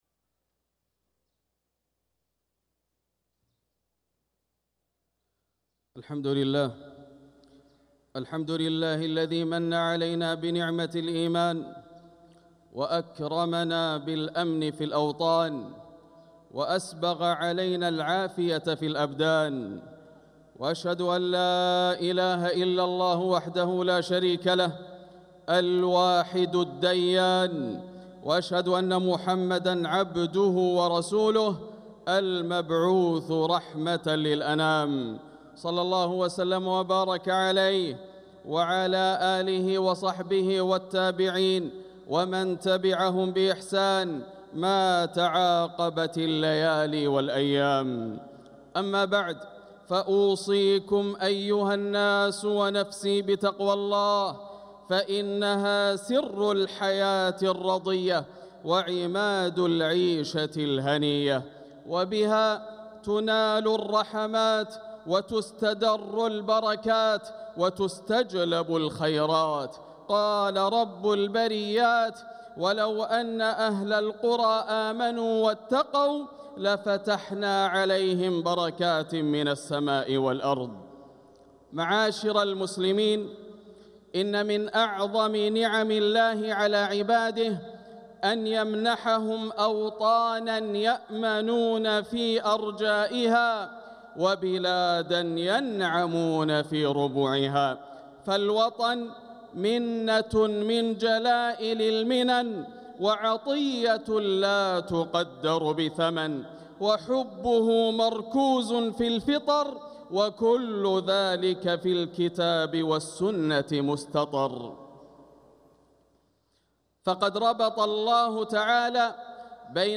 خطبة الجمعة 4 ربيع الآخر 1447هـ بعنوان حب الوطن في ضوء الكتاب والسنة > خطب الشيخ ياسر الدوسري من الحرم المكي > المزيد - تلاوات ياسر الدوسري